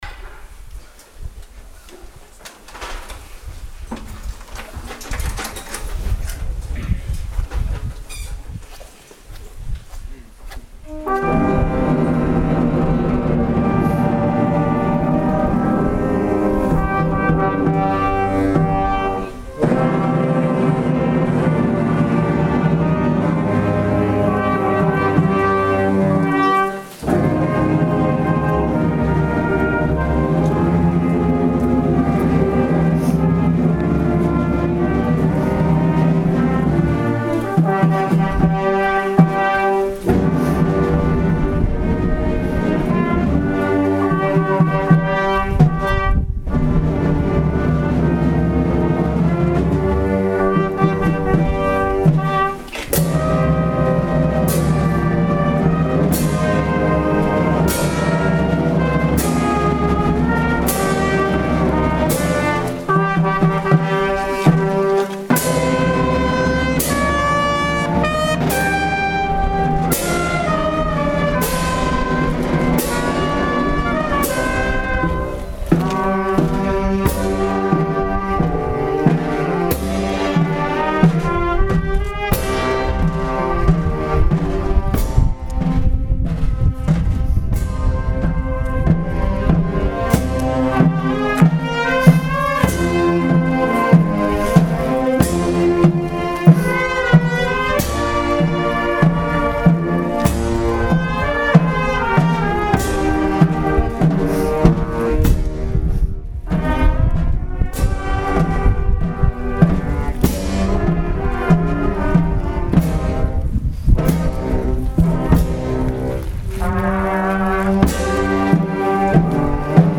Com’è tradizione, anche a Gambatesa si è tenuta l’Azione liturgica e la conseguente processione di Cristo morto.
Gesù Morto In Processione
Marcia Funebre, Banda Donato Di Maria